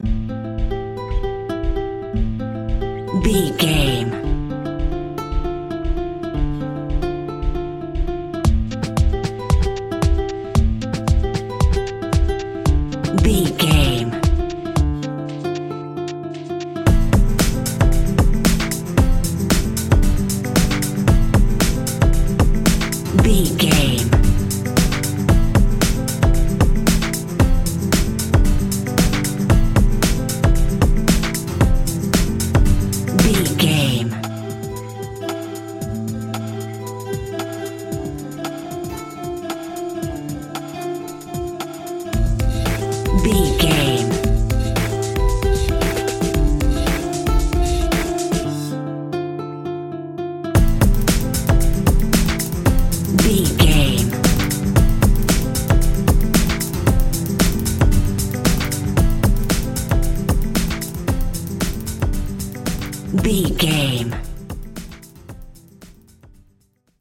Aeolian/Minor
calm
repetitive
soft
acoustic guitar
drums
drum machine
strings
Lounge
chill out
laid back
relaxed
nu jazz
downtempo
lift music
synth drums
synth leads
synth bass